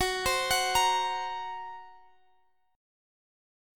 Listen to GbMb5 strummed